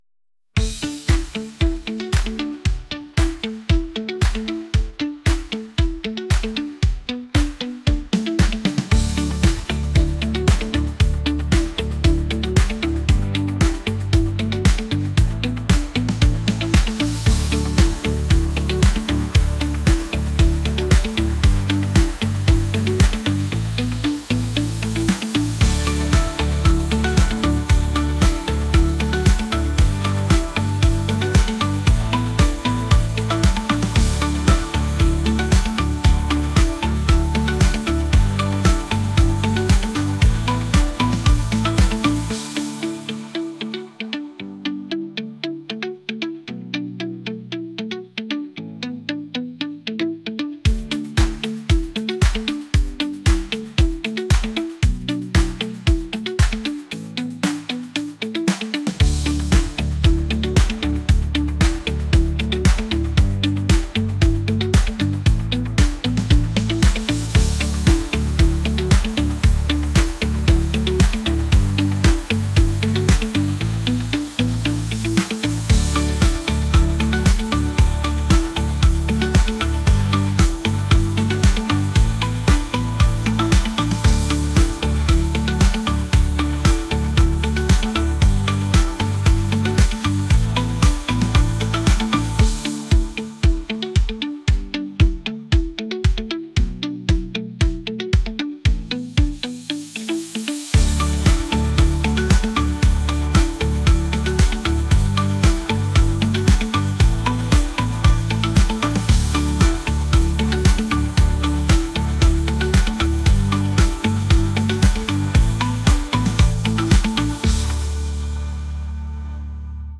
pop | indie | acoustic